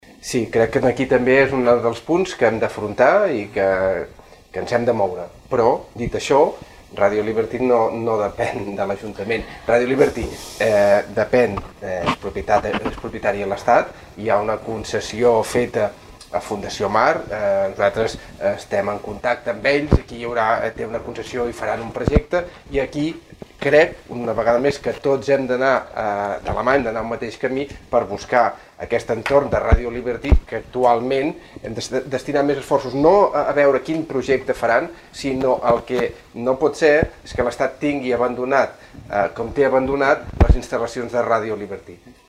L’Hotel Mas de Torrent ha estat l’escenari de la celebració del darrer debat dels 10 que us ha ofert Ràdio Capital, i que ha girat al voltant dels candidats que aspiren a prendre possessió de l’alcaldia de Pals els propers quatre anys.
Ha estat un debat tranquil i pausat en el que la radiografia política de la ciutat ha resultat força nítida, i de ben segur que els ciutadans n’hauran tret una idea força aproximada de les propostes de cada candidat per Pals.